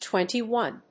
/ˈtwɛntiˌwɔn(米国英語), ˈtwenti:ˌwɔ:n(英国英語)/
アクセントtwénty‐óne